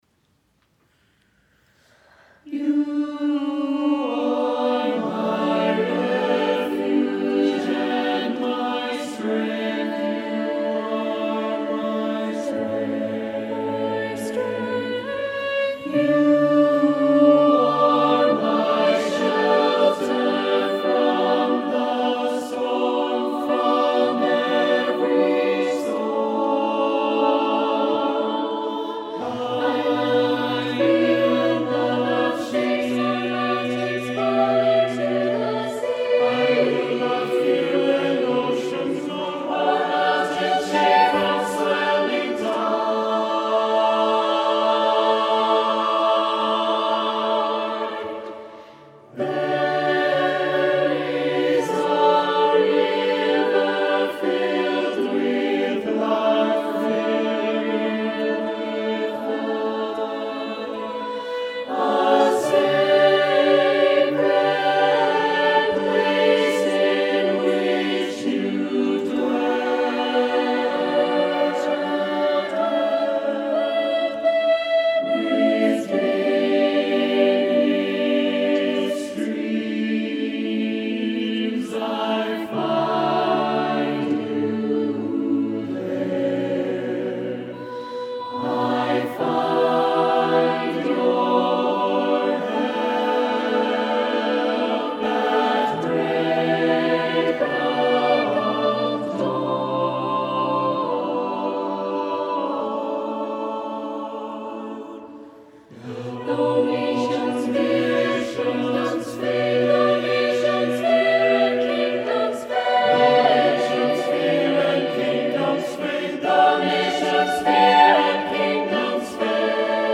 SATB, unaccompanied
Voicing: SATB